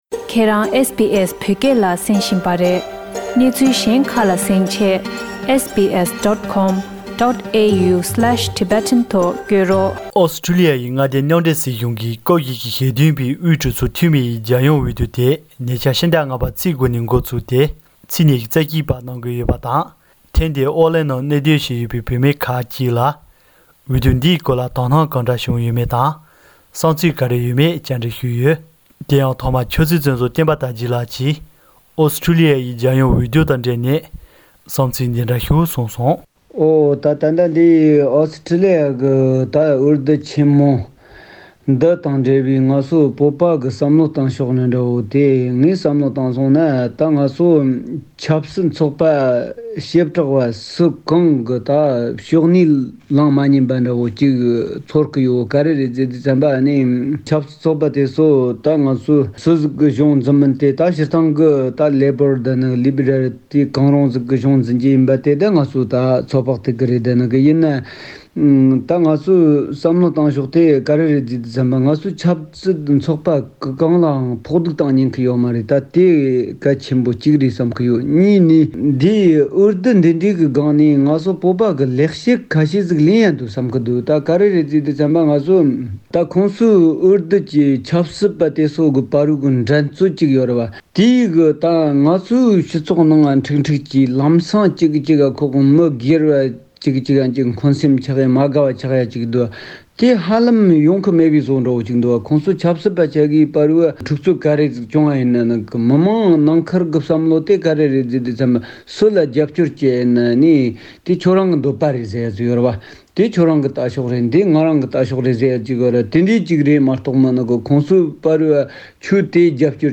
ཨོ་སི་ཊོ་ལི་ཡའི་མངའ་སྡེ་མཉམ་འབྲེལ་རྒྱལ་ཁབ་ཀྱི་ཕྱི་ལོ་ ༢༠༢༢ ལོའི་འོས་བསྡུ་ཆེན་མོ་དངོས་སུ་འགོ་འཛུགས་གནང་ཡོད་པ་དང་། ཨོ་གླིང་ནང་ཡོད་པའི་བོད་མི་ཁག་ཅིག་ལ་སྤྱིར་འོས་བསྡུ་འདི་བོད་པའི་རྩ་དོན་དང་ལྷག་པར་དུ་ཨོ་གླིང་ནང་གནས་སྡོད་བྱས་ཡོད་པའི་བོད་མི་ཚོས་དོ་སྣང་སྤྲད་རྒྱུ་གལ་ཆེན་པོ་གང་འདྲ་ཆགས་ཡོད་མེད་སྐོར་བཅར་འདྲི་ཞུས་ཡོད།